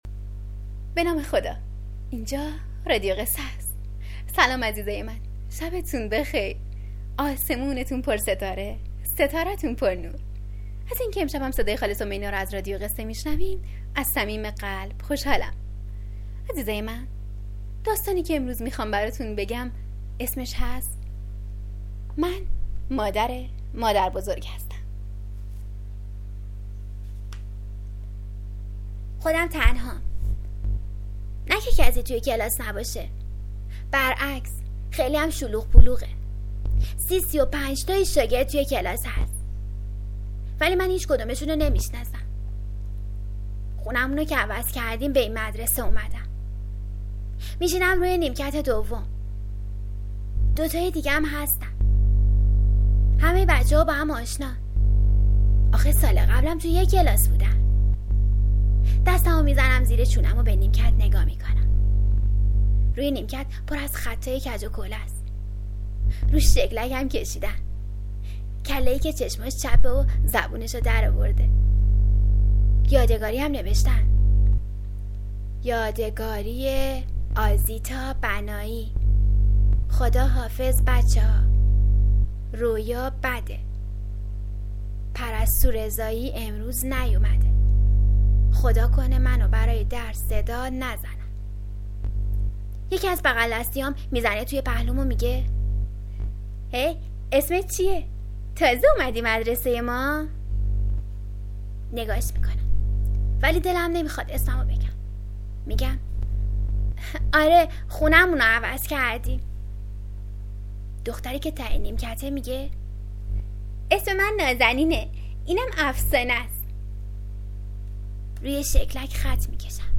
قصه کودکانه صوتی من مادر مادر بزرگم هستم